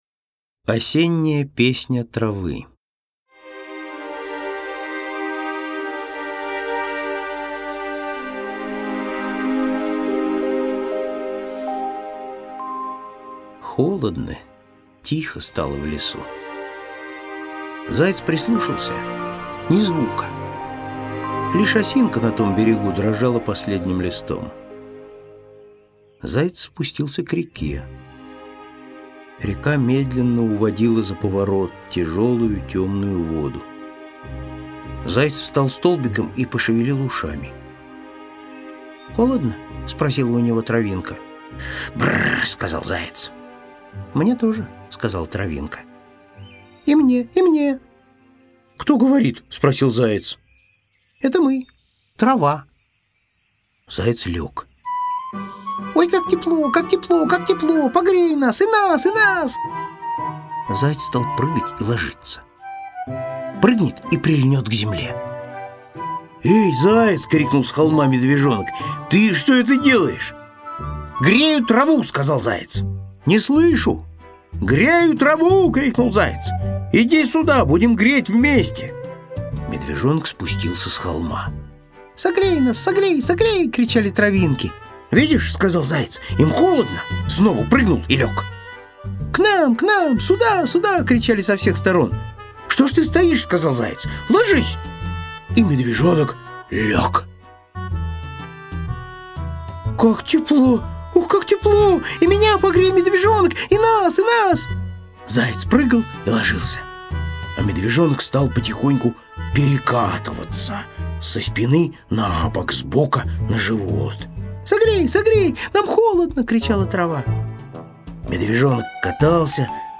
Осенняя песня травы – Козлов С.Г. (аудиоверсия)